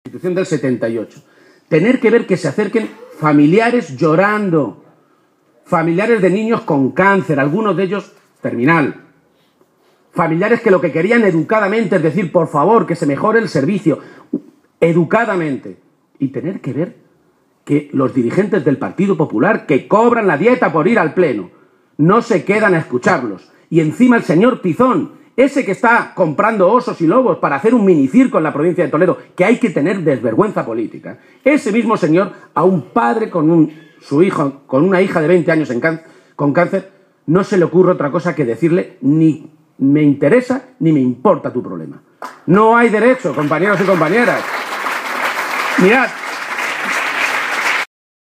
El secretario general del PSOE de Castilla-La Mancha, Emiliano García-Page, ha protagonizado esta tarde un acto electoral en la localidad toledana de Illescas en el que ha dicho que la campaña empieza a ir muy bien para el PSOE y ha animado a oos militantes, simpatizantes y votantes socialistas a seguir “porque a nosotros no nos van a quitar la moral Gobiernos como los de Rajoy y Cospedal, que no tienen moral”.